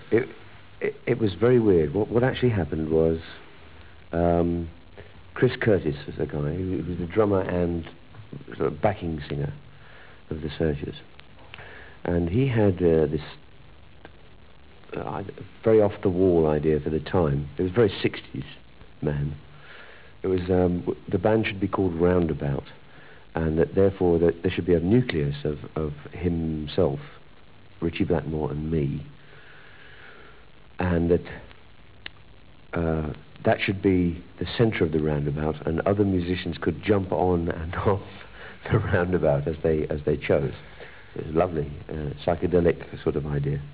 THE TOMMY VANCE INTERVIEWS